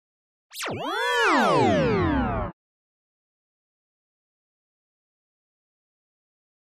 Short Wave Hit Fluctuating Electronic Buzz Wash